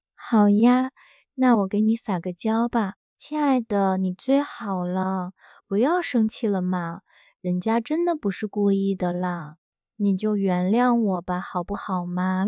Step-Audio-TTS-3B
tone_control.wav